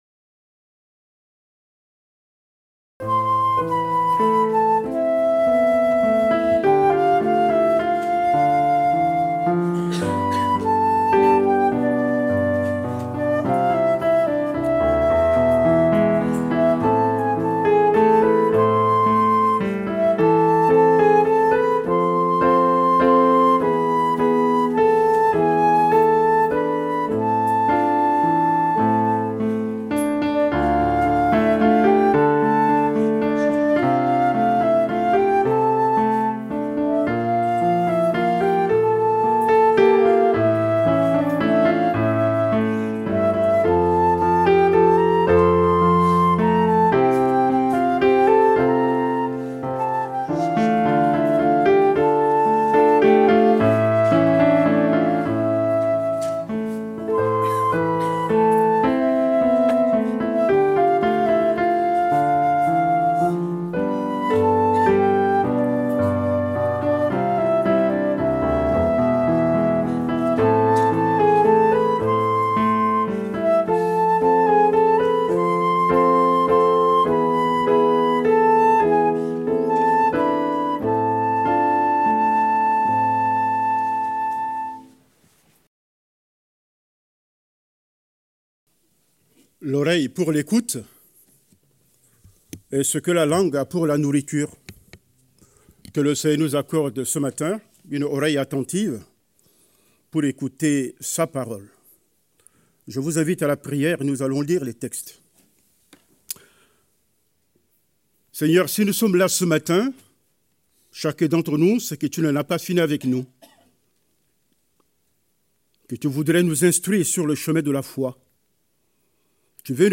Prédication du 09 novembre 2025.